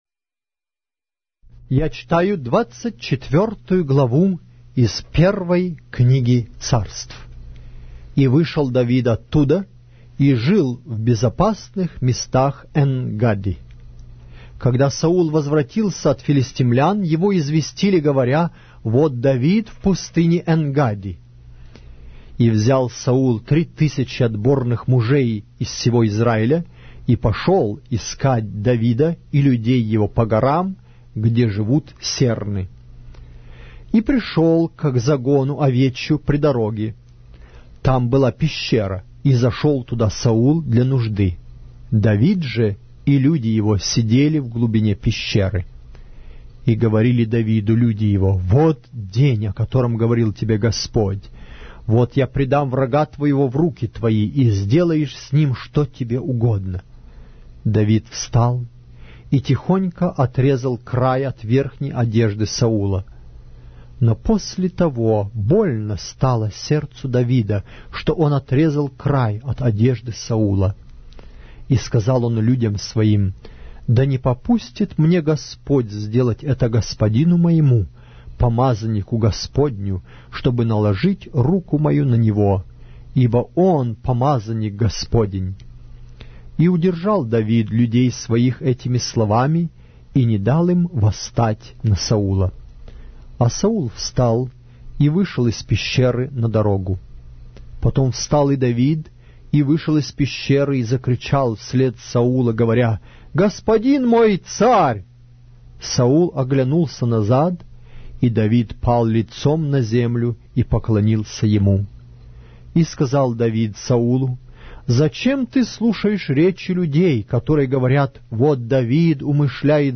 Глава русской Библии с аудио повествования - 1 Samuel, chapter 24 of the Holy Bible in Russian language